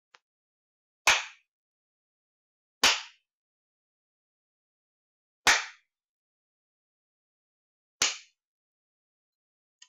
taps2.wav